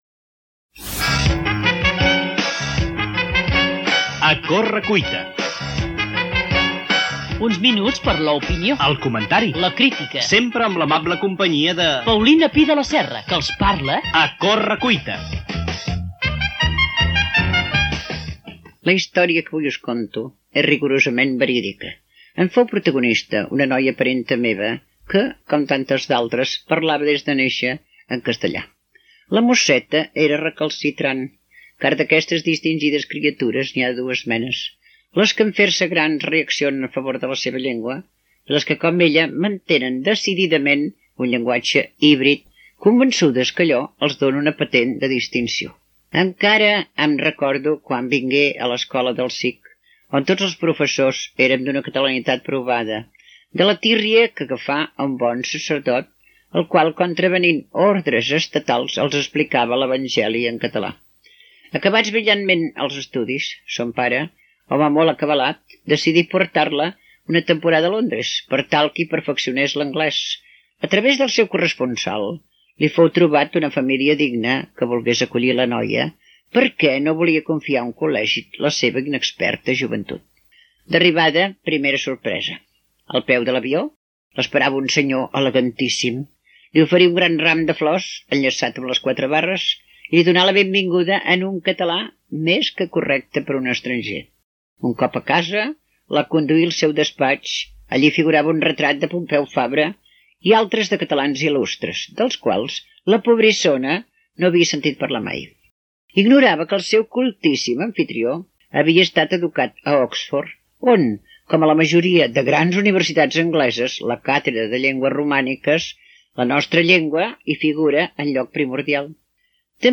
Àudios: arxiu històric de Ràdio Terrassa